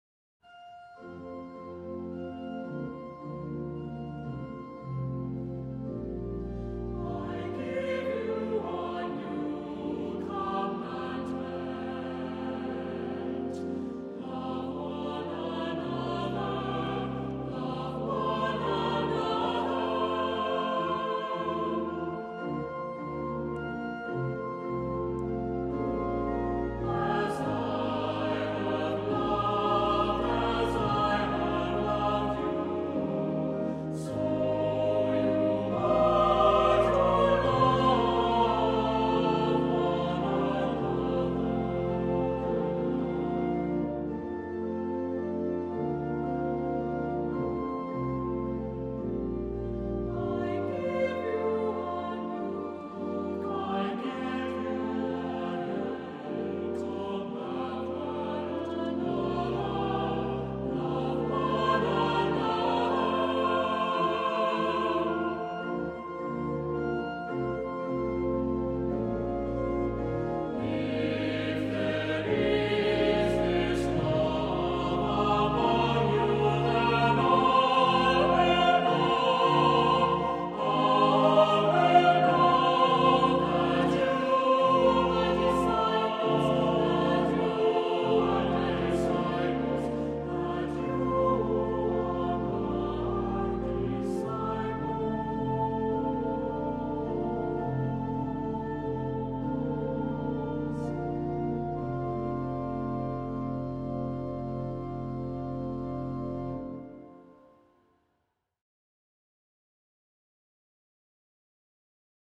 Accompaniment:      With Organ
Music Category:      Christian
this anthem is a must-have for your library.